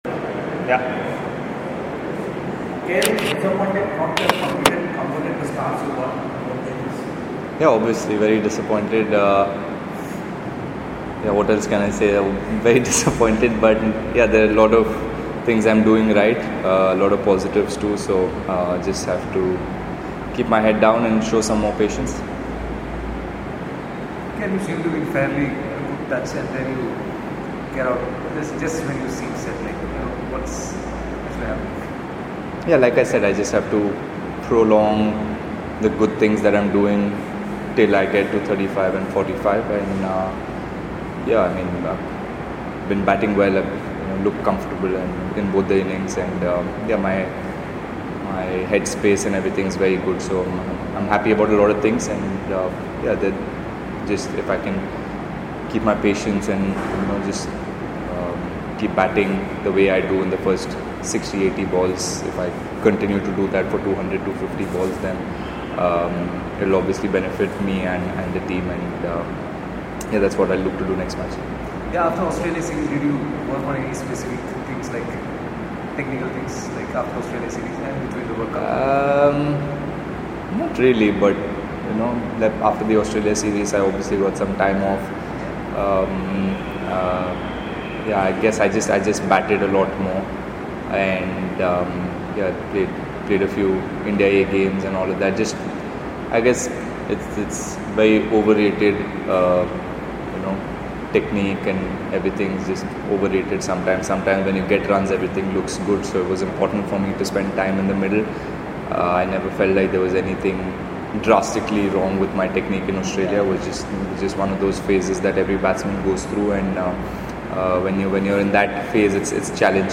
KL Rahul, Member, Indian Cricket Team. He spoke to the media in Antigua on Saturday after Day 3 of the the 1st Test against West Indies.